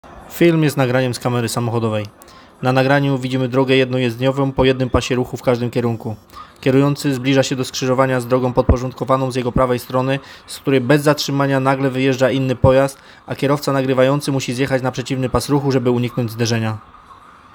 Nagranie audio audiodeskrypcja nagrania